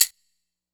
Closed Hats
pbs - reggae II [ Hihat ].wav